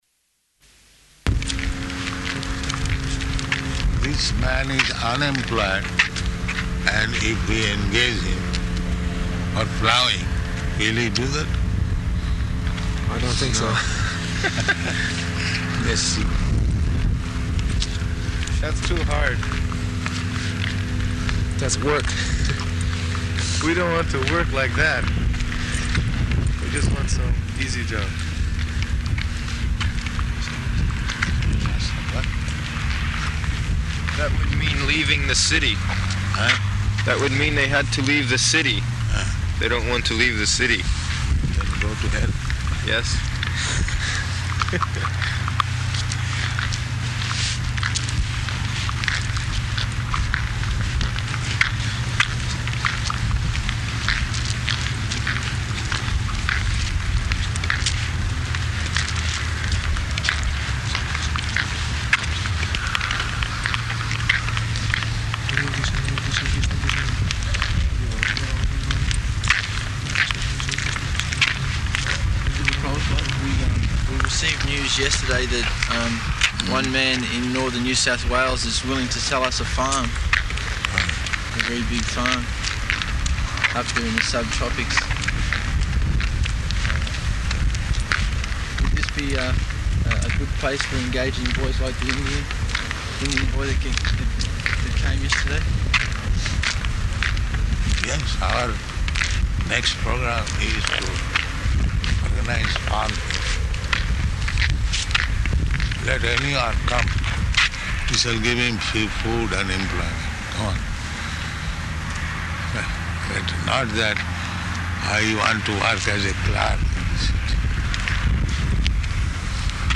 Morning Walk --:-- --:-- Type: Walk Dated: May 15th 1975 Location: Perth Audio file: 750515MW.PER.mp3 Prabhupāda: This man is unemployed, and if we engaged him for plowing, will he do that?